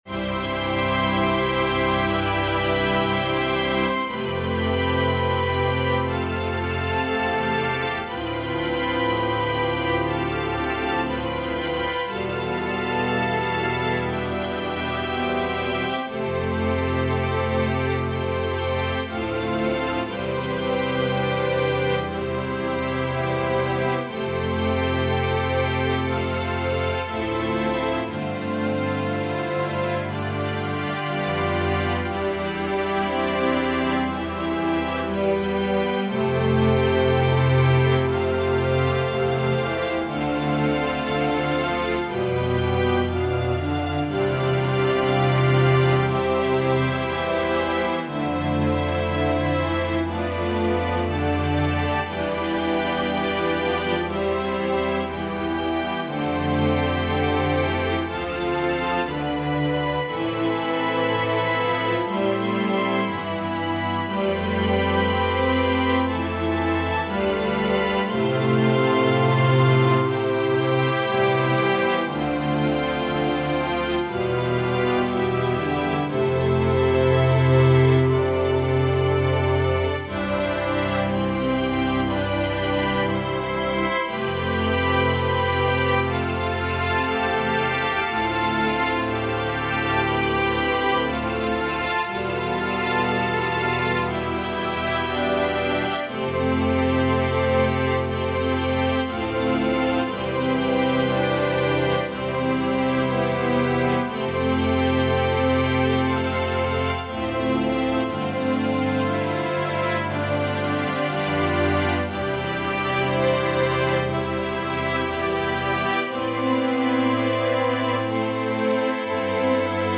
organ duet
Voicing/Instrumentation: Organ/Organ Accompaniment